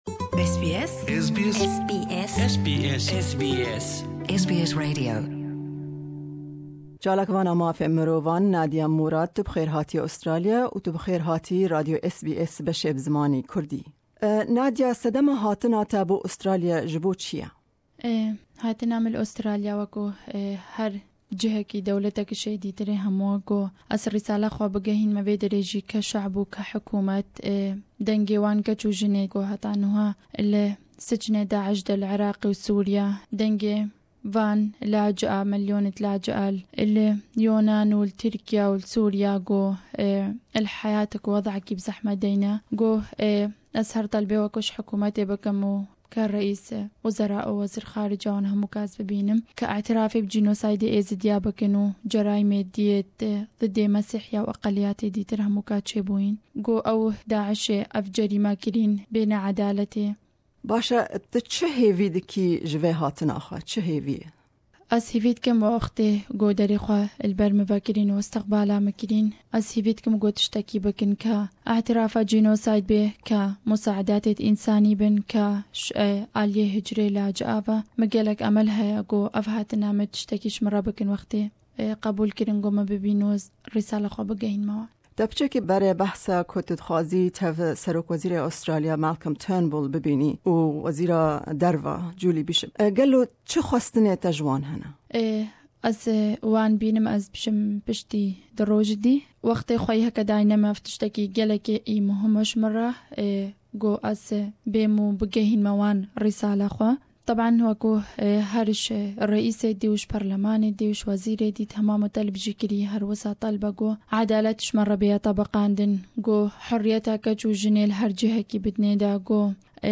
Me hevpeyvînek bi Nadia Murad re sebaret bi hatina wê bo Australya û hêvî û daxwazên wê ji hukmata Australya çine pêk anî.